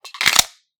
gun_magazine_insert_empty_3.ogg